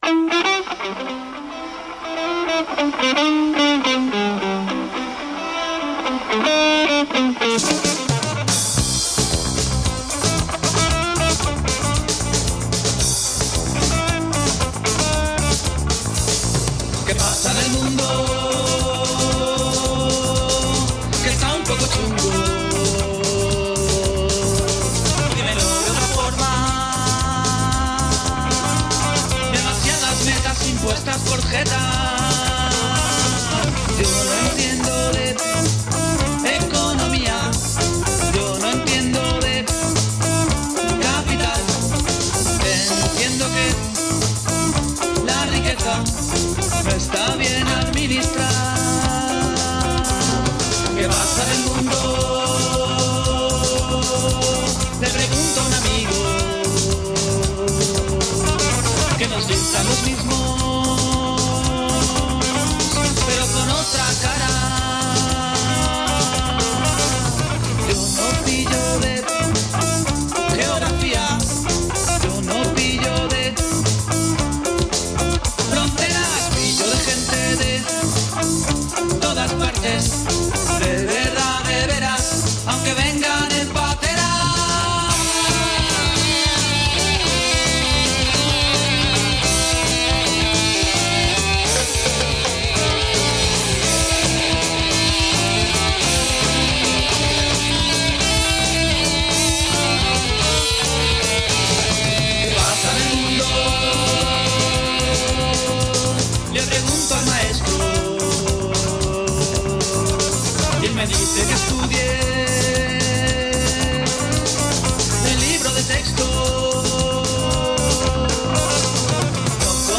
Lectura de un comunicado emitido por una asociación libertaria del barrio de Sants, referente al conflicto que se ha generado, a raíz del intento de derribo del centro social Can Vies, por parte el gobierno de Catalunya,